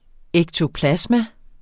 Udtale [ εgtoˈplasma ]